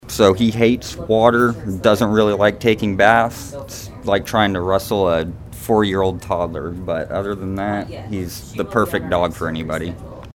Keegan Hates Water.mp3